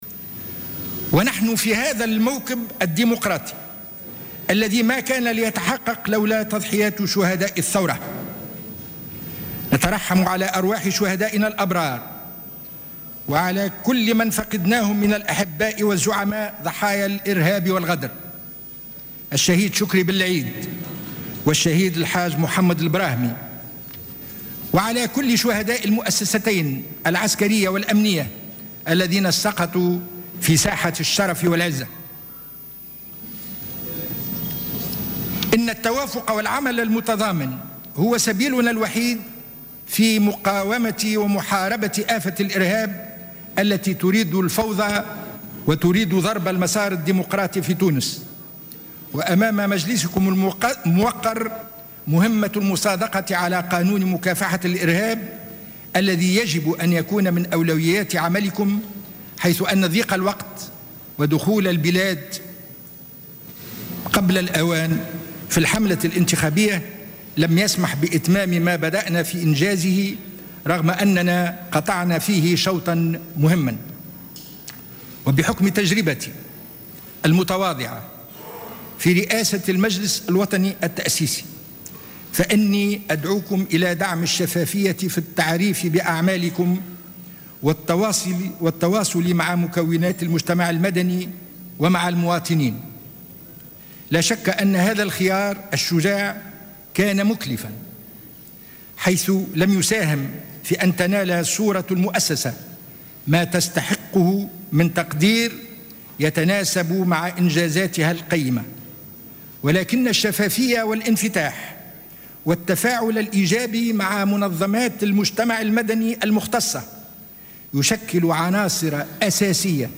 Le président sortant de l’Assemblée nationale constituante, Mustapha Ben Jaâfar a prononcé une allocution à l'occasion de la séance inaugurale de l'Assemblée des représentants du peuple, mardi 02 décembre 2014, lors de laquelle il a annoncé officiellement la clôture des travaux de l'ancienne assemblée.